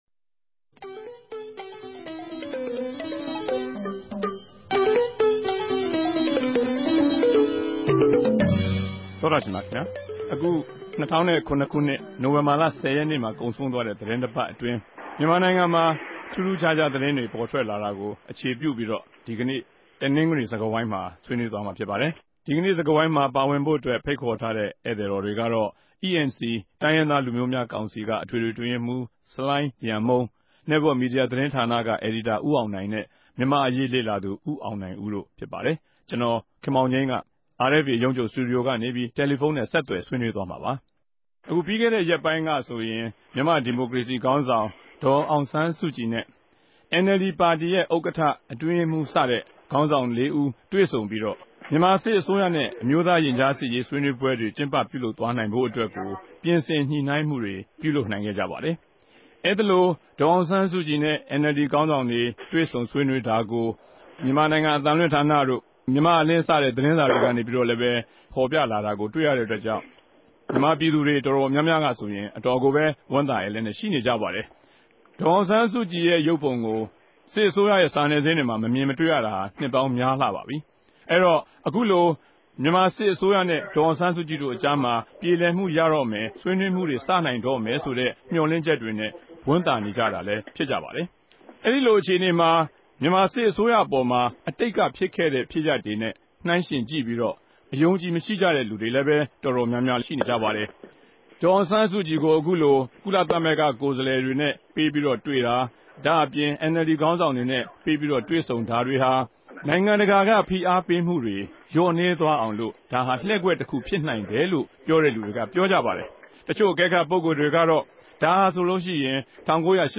ဒီသတင်းကို အေူခခ္ဘံပီး အခုတပတ် တနဂဿေိံြ စကားဝိုင်းမြာ သုံးသပ် ဆြေးေိံြးထားပၝတယ်။